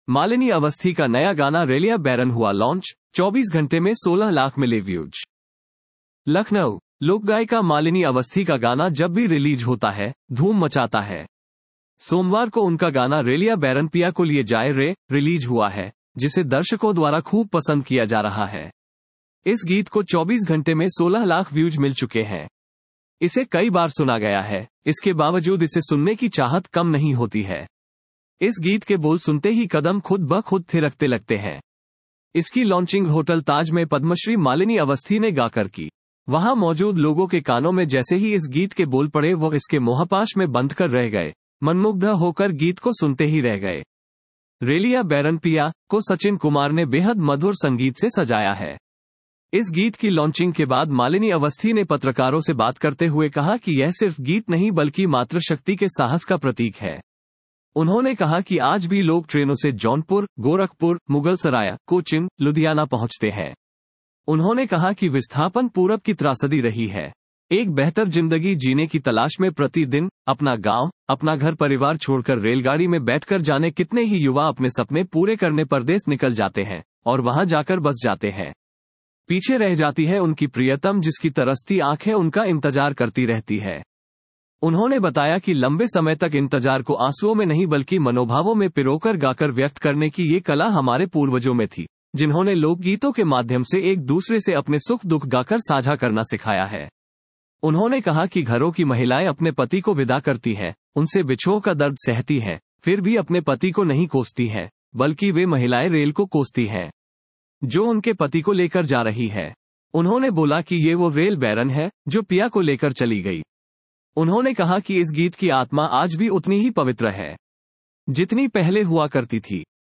लोकगायिका